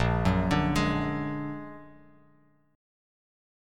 A#+7 chord